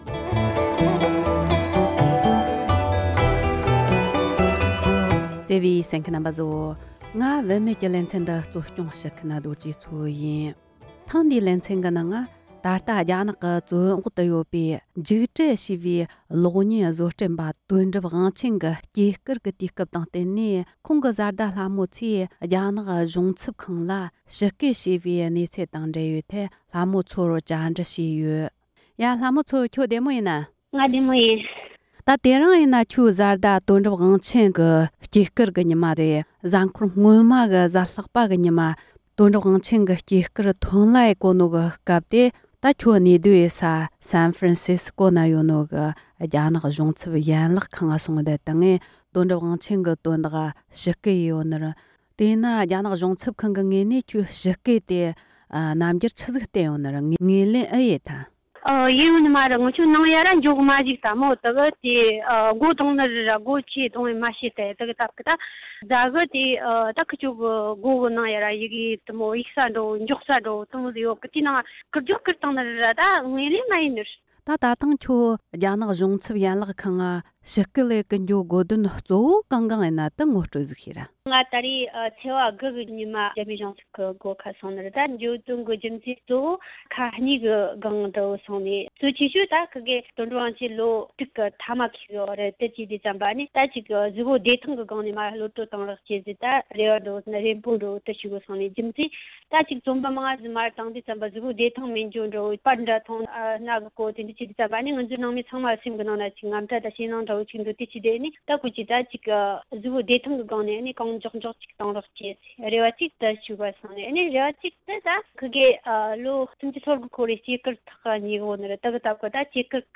ཞིབ་ཕྲའི་གནས་ཚུལ་བཅར་འདྲི་ཞུས་པར་གསན་རོགས་གནོངས༎